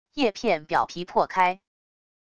叶片表皮破开wav音频